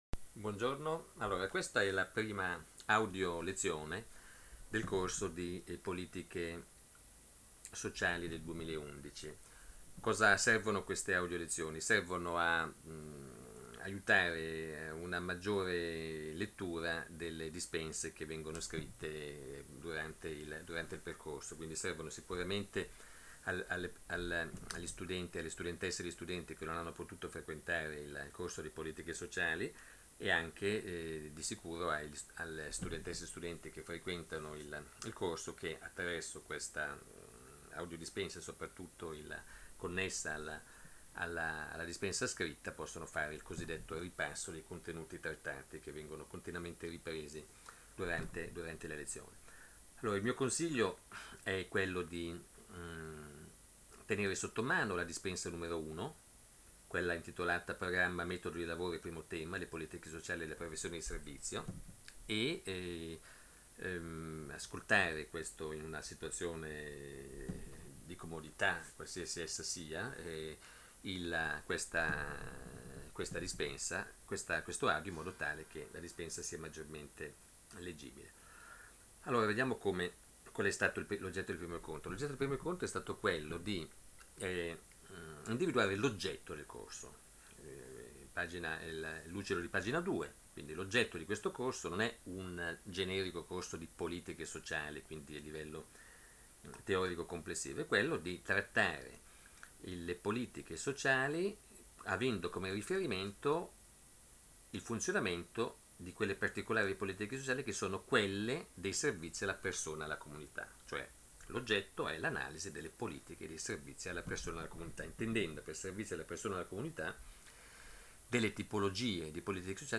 Esempio: Audio lezione Dispensa didattica n. 1.Mp3